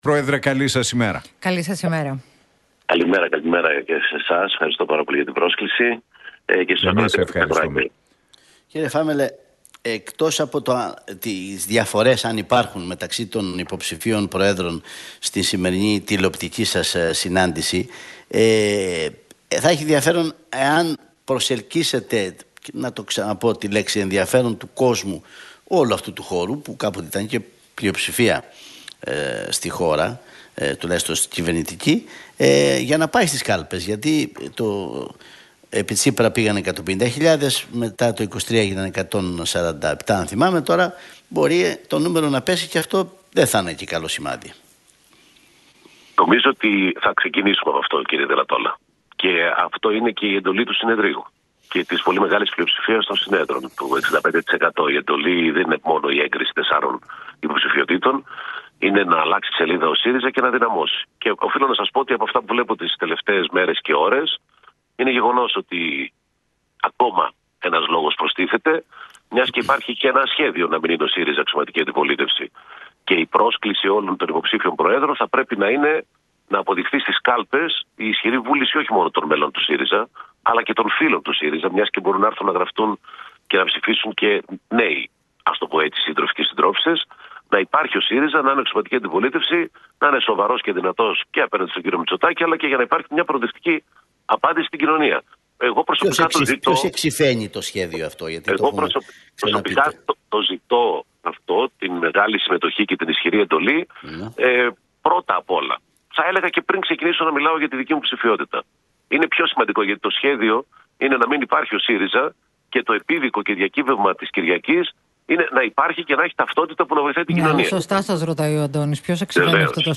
Φάμελλος στον Realfm 97,8: Υπάρχει σχέδιο να μην είναι αξιωματική αντιπολίτευση ο ΣΥΡΙΖΑ